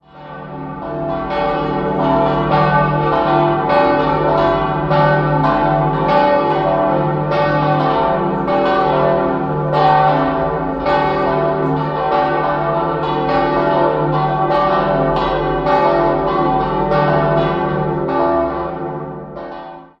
Rupertglocke ("Heiderglocke") cis'+11 1.650 kg 1.350 mm 1946 Gugg, Straubing Marienglocke e'+4 1.050 kg 1.180 mm 1946 Gugg, Straubing Petrusglocke fis'+10 550 kg 1.030 mm 1946 Gugg, Straubing Josefsglocke a'+4 400 kg 860 mm 1946 Gugg, Straubing